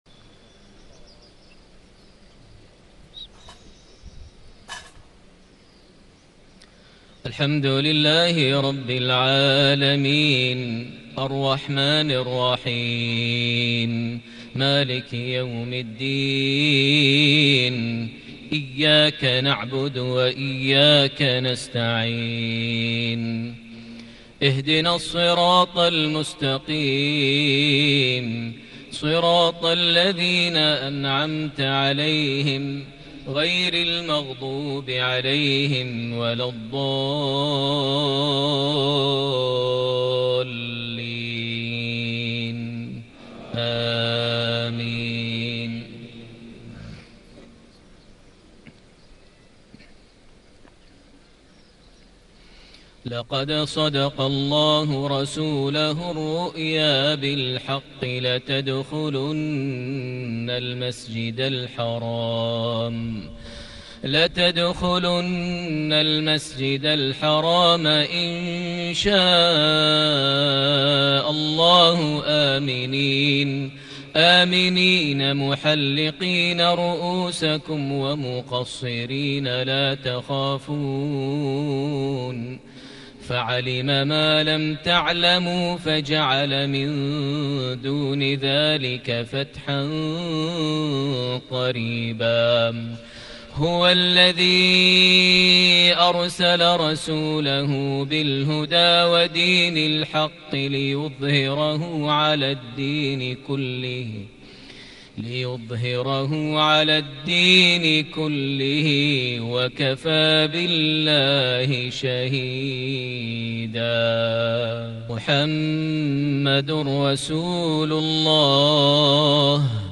صلاة المغرب 1-5-1440هـ من سورة الفتح | Maghrib prayer from Surah Al fath 7-1-2019 > 1440 🕋 > الفروض - تلاوات الحرمين